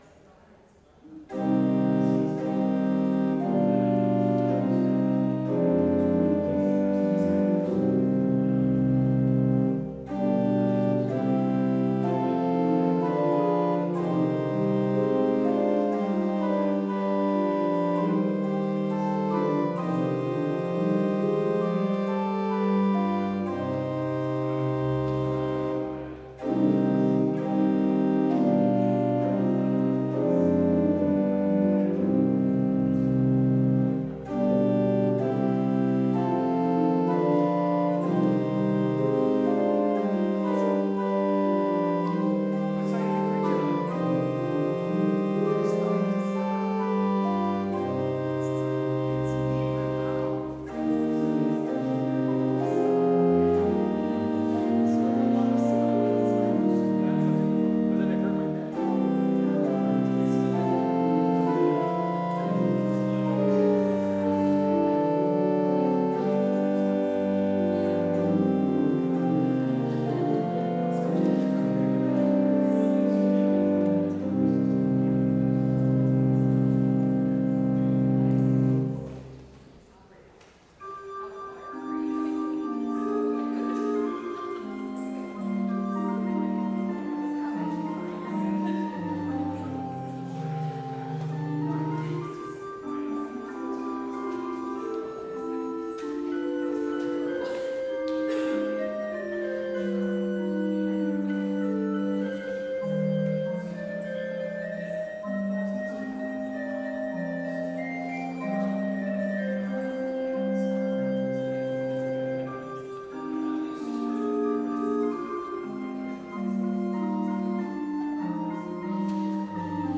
Complete service audio for Chapel - Wednesday, June 5, 2024